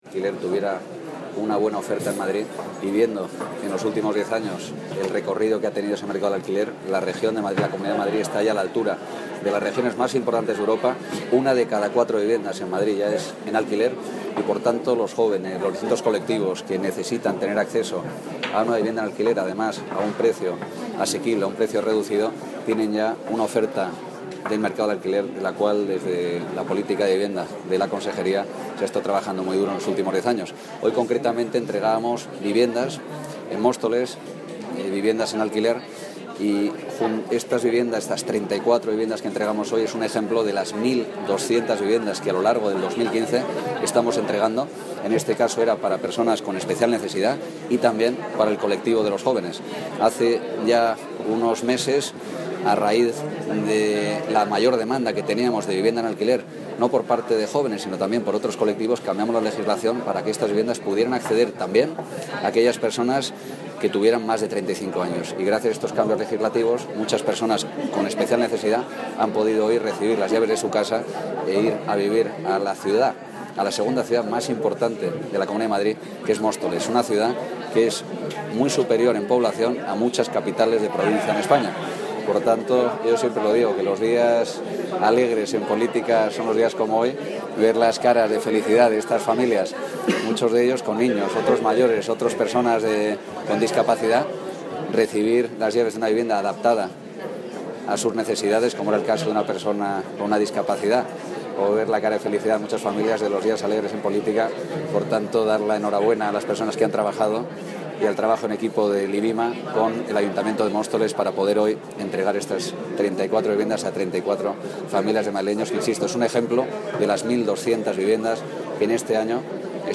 Audio - Pablo Cavero (Consejero de Transportes, Infraestructuras y Vivienda) Sobre Viviendas